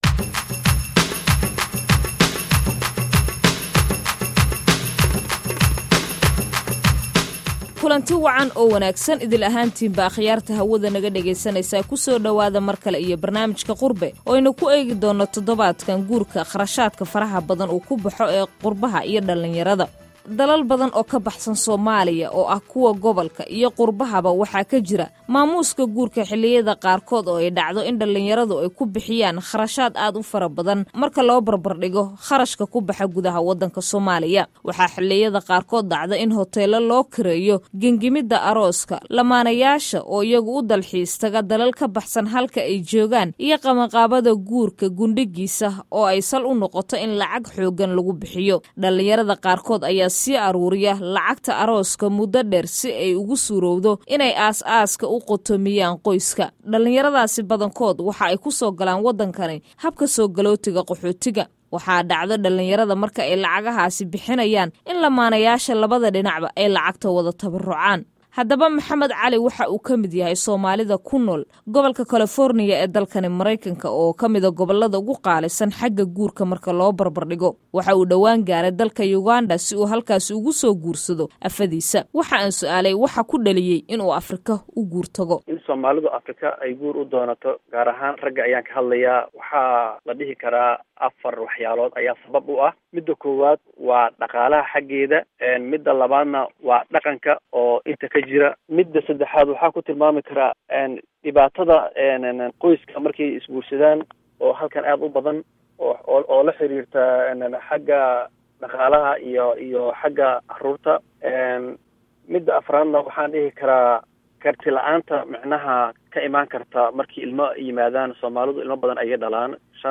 Barnaamijka ayaa waxaa ka qayb qaadanaya khubaro iyo dad waaya aragtinimo u leh arrinatn